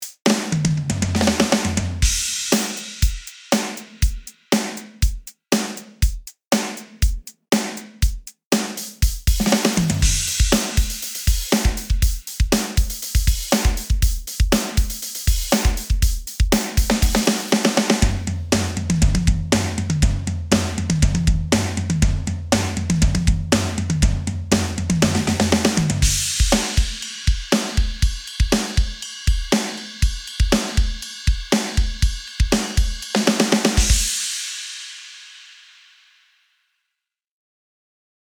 比較のため、上と同じパターンを SOFT や HEAVY にするとどうなるかも聴いてみてください。
HEAVY
MTPDK_demo_heavy.mp3